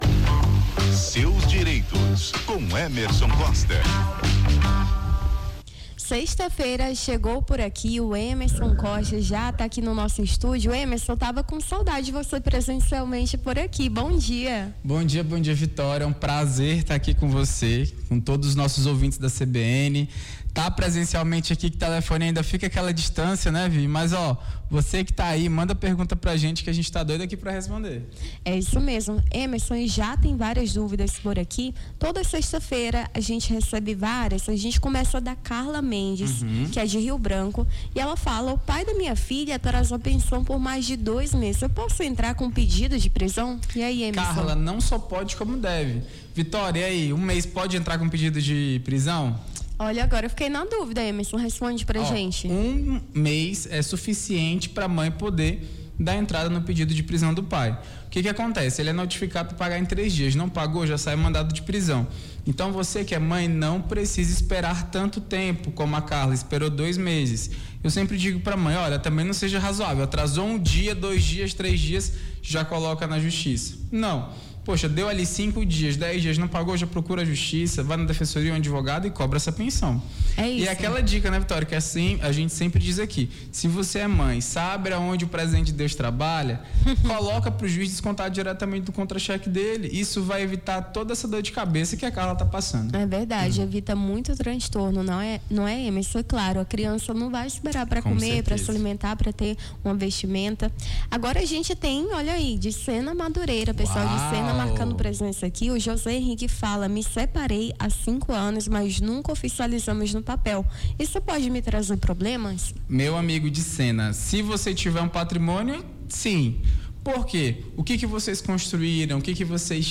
Seus Direitos: advogado tira dúvidas sobre direito de família